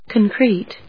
音節con・crete 発音記号・読み方
/kὰnkríːt(米国英語), kˈɔŋkriːt(英国英語)/